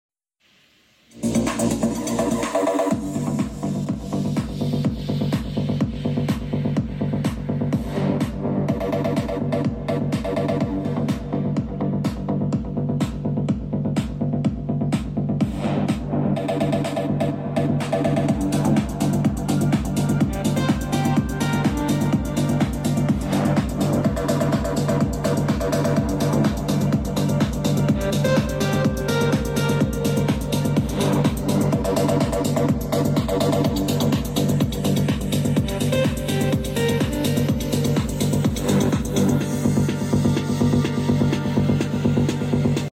Home theater Samsung tocando no sound effects free download